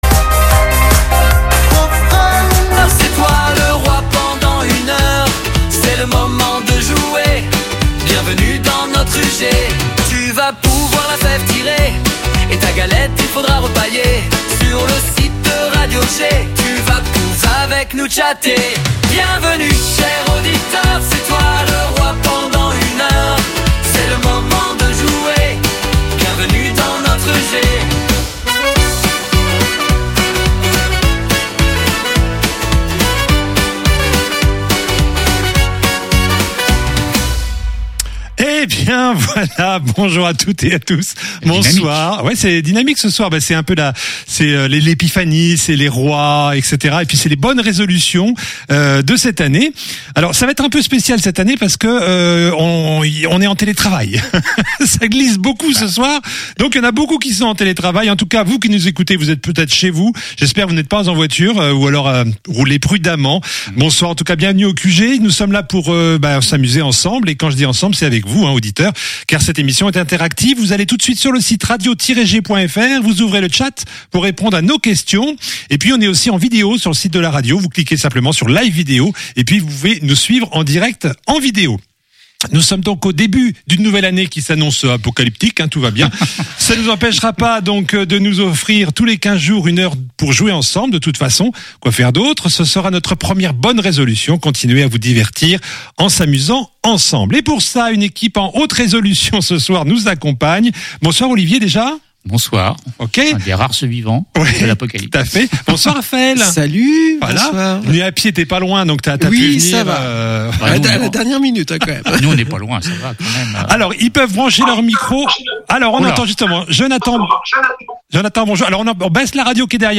Le QG, le programme radio de jeux de Radio G!